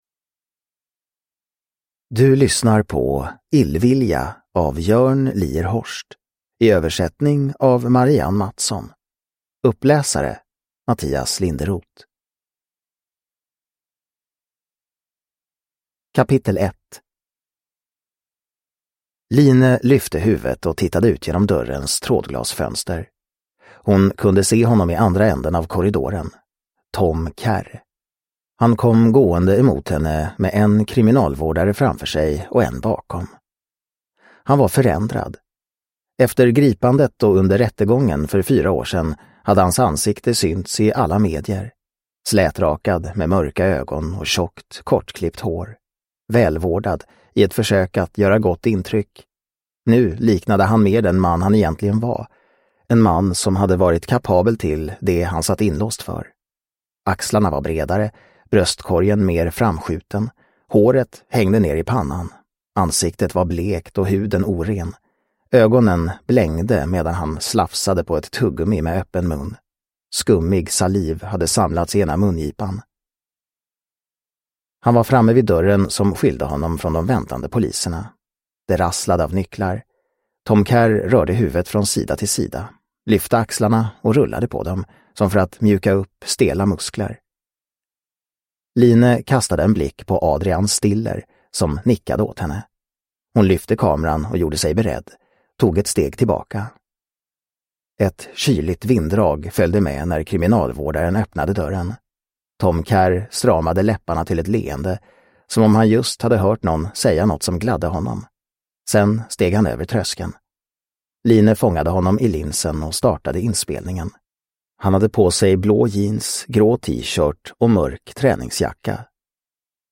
Illvilja – Ljudbok – Laddas ner